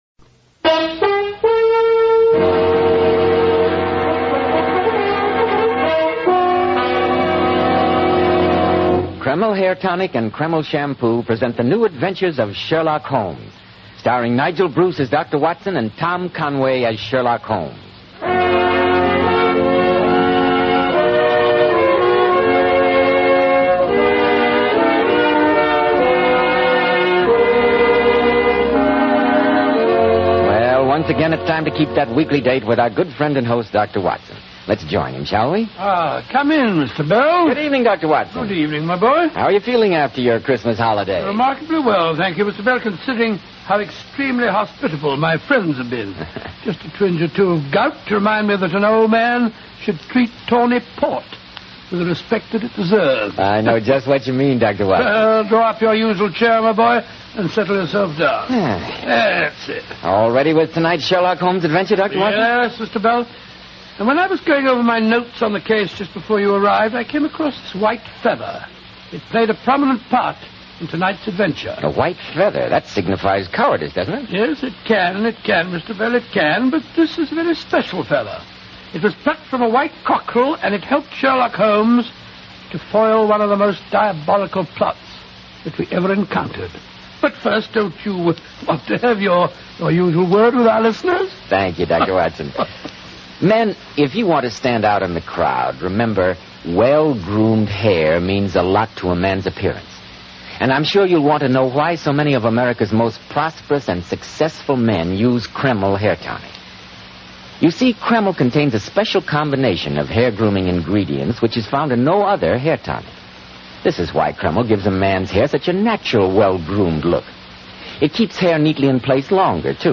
Radio Show Drama with Sherlock Holmes - The White Cockerel 1946